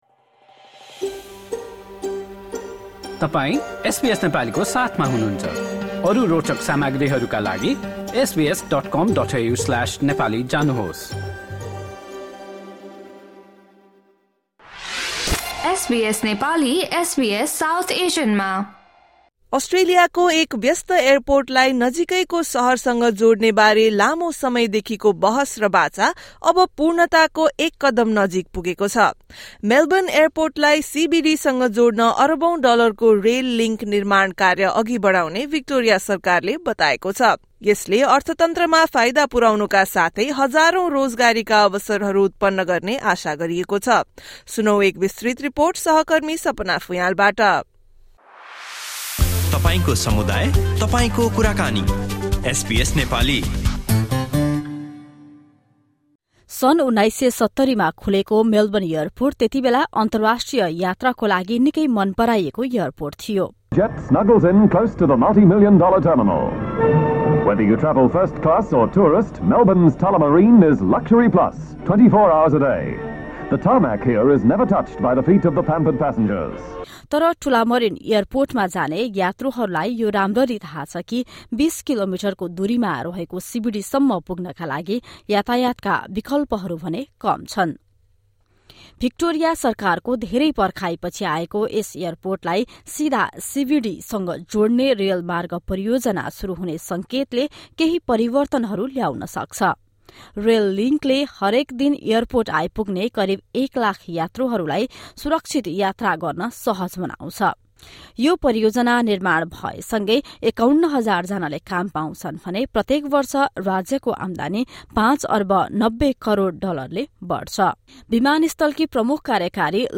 भिक्टोरियाको व्यस्त मेलबर्न एयरपोर्टलाई सीबीडीसँग जोड्न अरबौँ डलरको रेल लिङ्क निर्माण कार्य अघि बढ्ने भिक्टोरिया सरकारले बताएको छ। यस विषयमा तयार पारिएको विस्तृत रिपोर्ट सुन्नुहोस्।